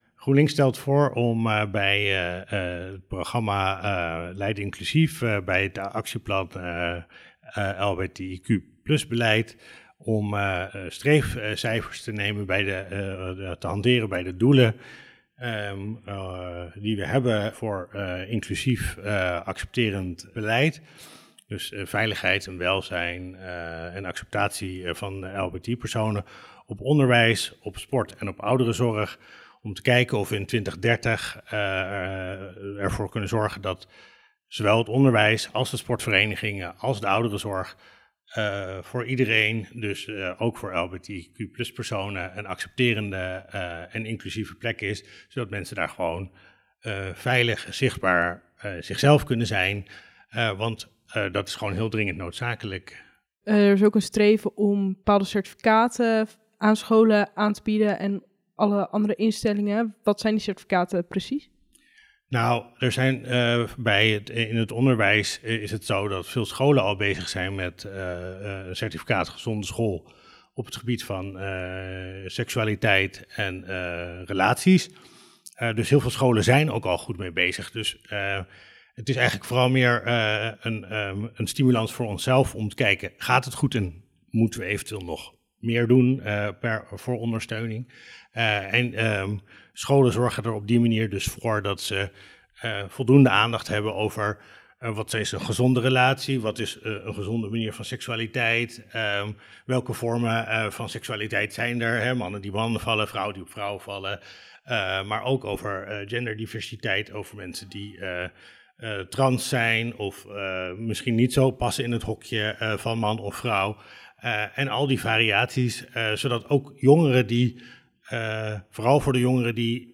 sprak met indiener en raadslid van GroenLinks Ivo van Spronsen over de motie